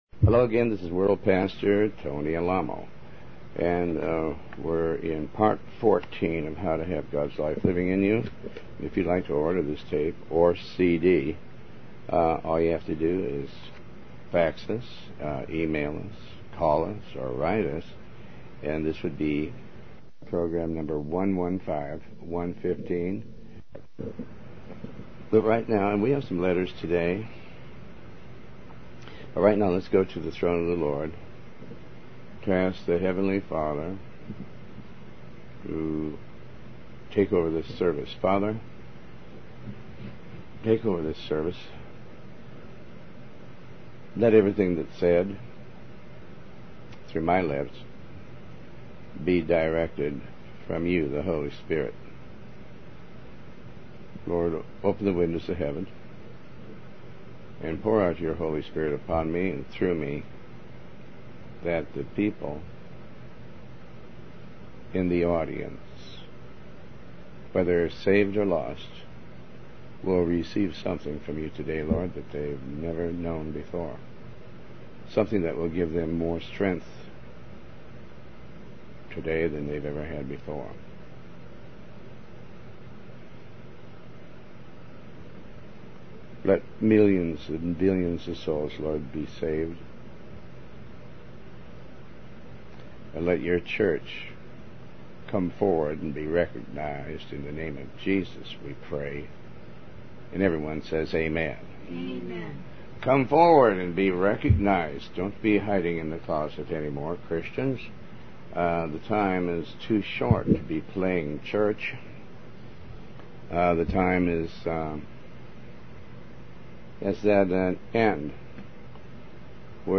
Talk Show Episode, Audio Podcast, Tony Alamo and Ep115, How To Have Gods Life Living In You, Part 14 on , show guests , about How To Have Gods Life Living In You, categorized as Health & Lifestyle,History,Love & Relationships,Philosophy,Psychology,Christianity,Inspirational,Motivational,Society and Culture